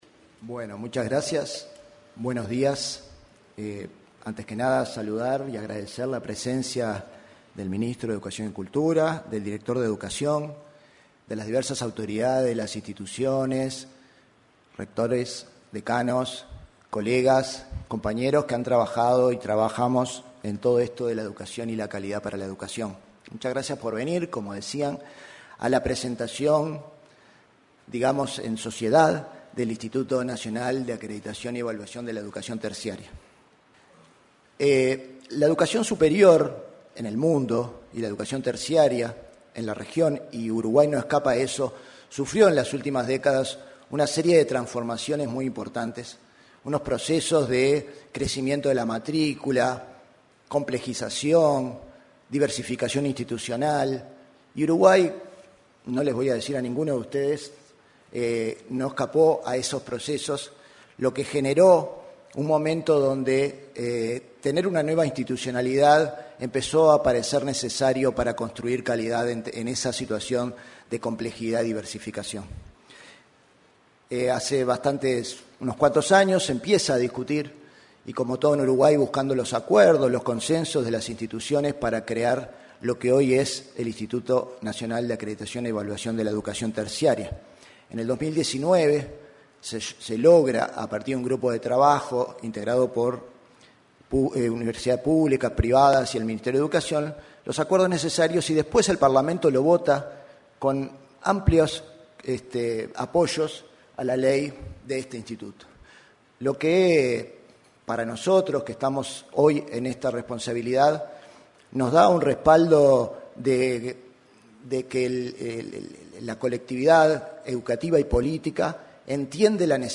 Ceremonia de presentación del Inaeet
Ceremonia de presentación del Inaeet 01/12/2025 Compartir Facebook X Copiar enlace WhatsApp LinkedIn En la presentación del Instituto Nacional de Acreditación y Evaluación de la Educación Terciaria, se expresaron su presidente, Ernesto Domínguez; el director nacional de Educación, Gabriel Quirici, y el ministro de Educación y Cultura, José Carlos Mahía.